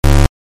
error_sound.mp3